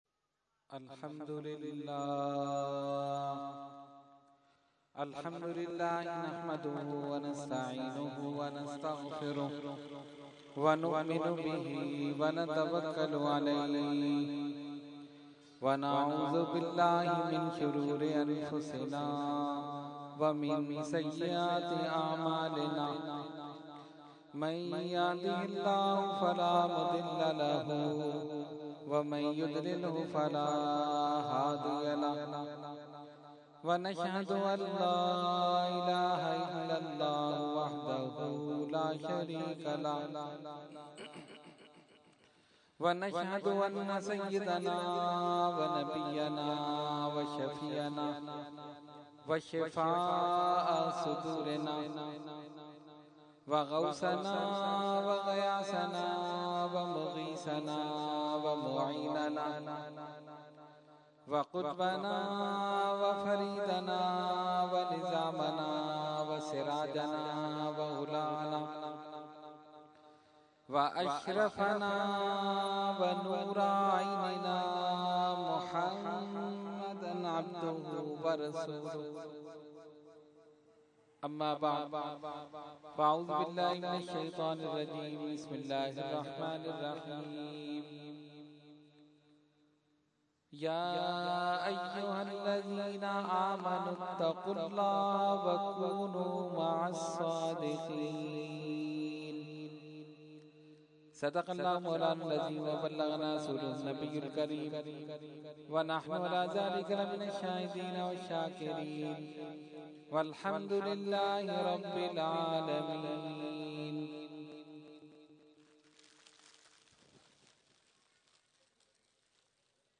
Category : Speech | Language : UrduEvent : Mehfil 11veen Nazimabad 22 February 2014